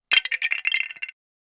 Legos Breaking
Lego-Breaking.mp3